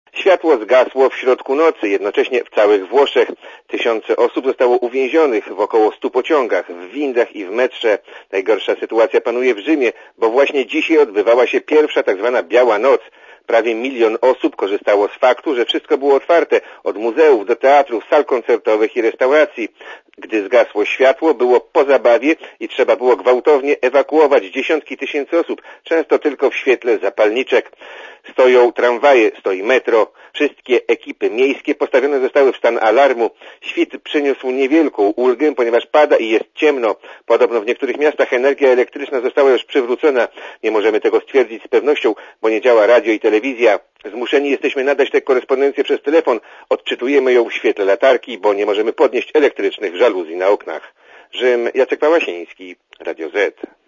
Korespondencja Radia Zet z Rzymu (212Kb)